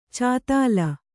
♪ cātāla